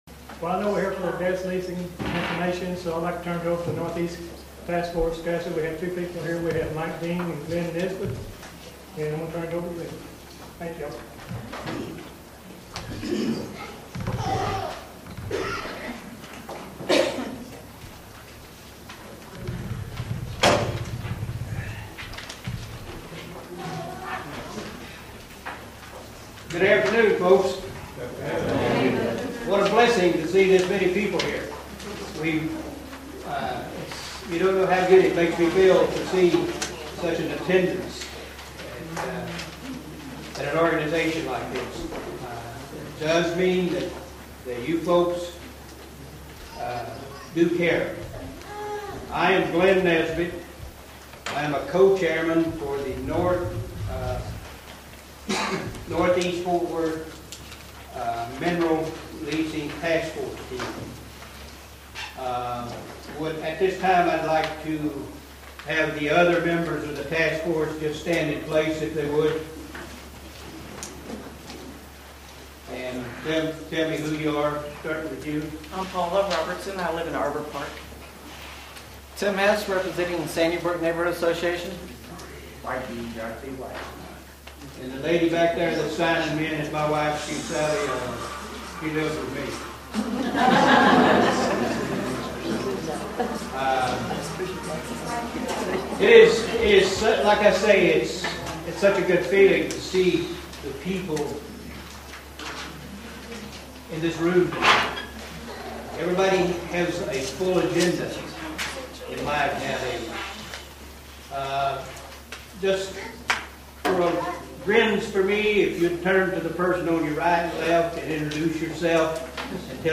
The following is the audio from the JTWNA meeting on 08/07/08 discussing the gas drilling leases.